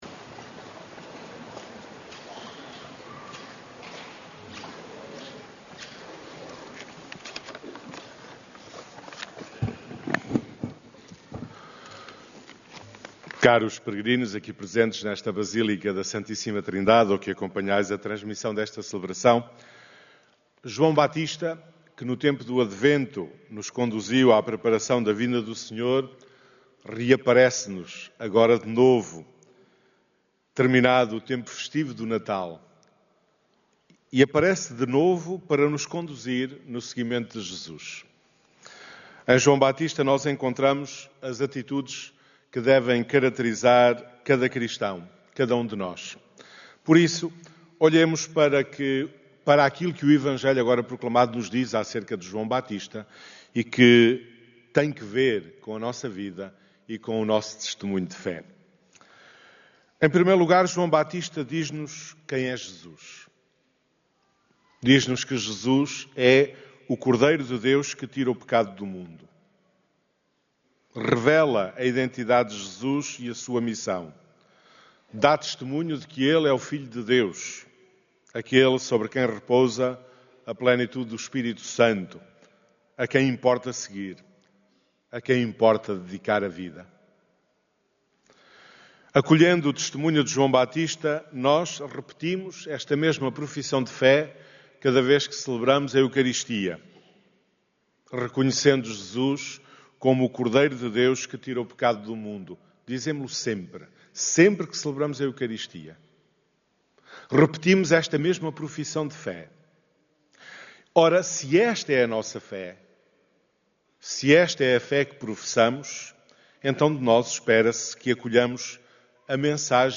Na homilia deste domingo, os peregrinos foram desafiados a um encontro pessoal com Cristo, através do exemplo de João Baptista e dos santos Pastorinhos.